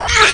pain3.wav